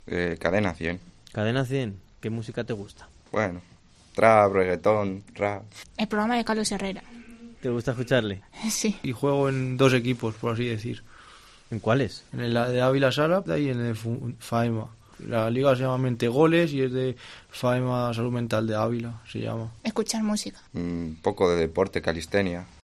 Los usuarios del programa nos han contado que les gusta, la música, el deporte y escuchar la radio...